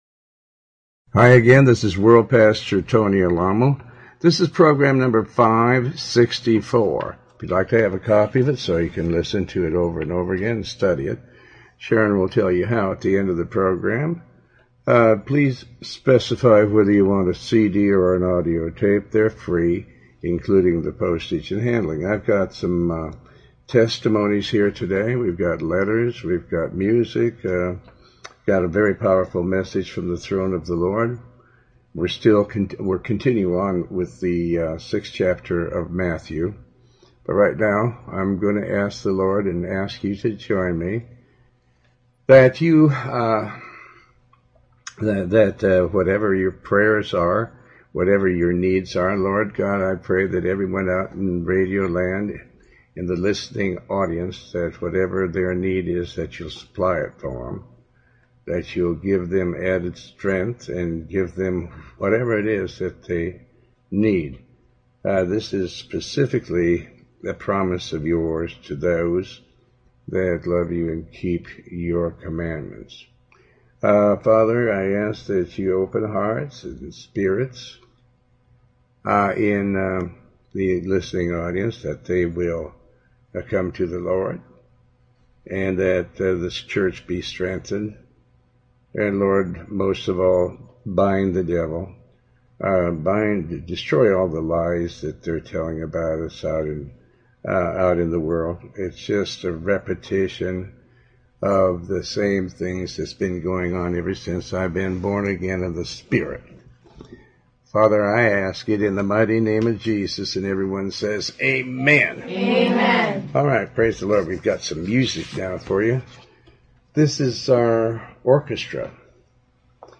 Testimonies to answer some of the lies of the media. Pastor's preach their messages from Tony Alamo's literature.